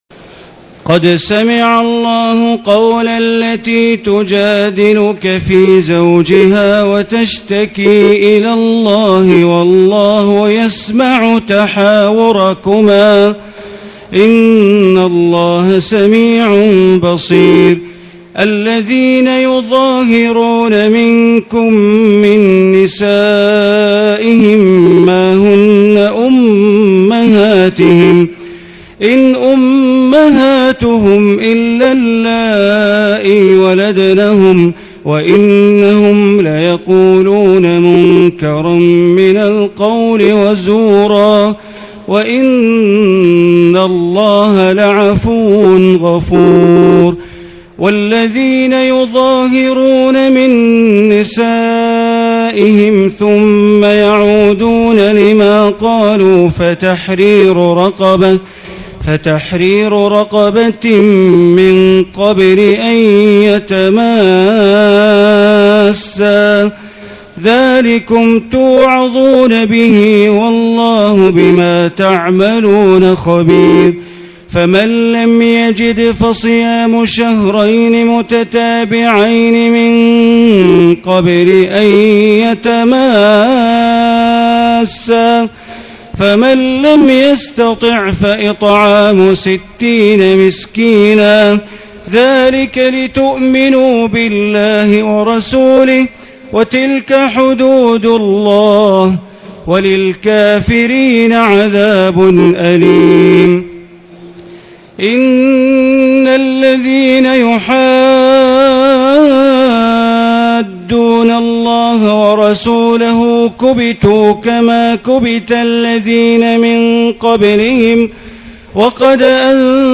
الليلة السابعة والعشرين من رمضان عام ١٤٣٨ من سورة المجادلة إلى سورة الصف آية ١٤ > تراويح ١٤٣٨ هـ > التراويح - تلاوات بندر بليلة